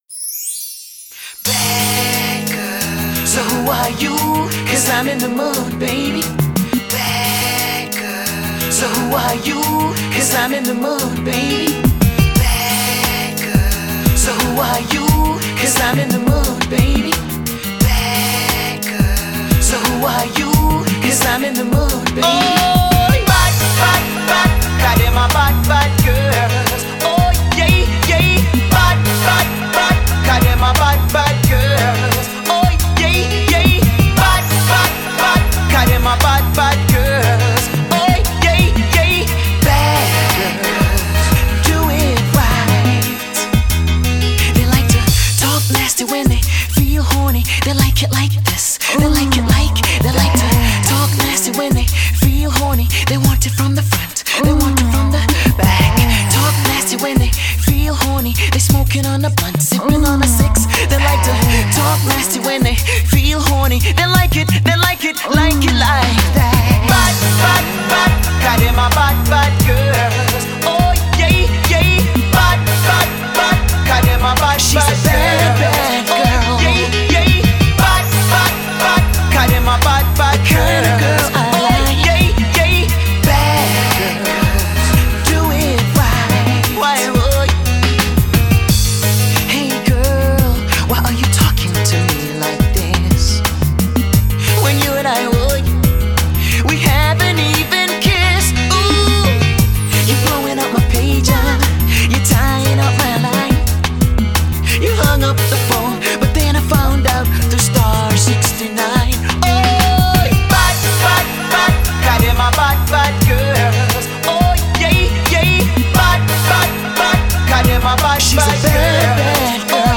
Dancehall
Jamaican Dancehall Artiste